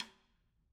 Percussion
Snare2-taps_v2_rr1_Sum.wav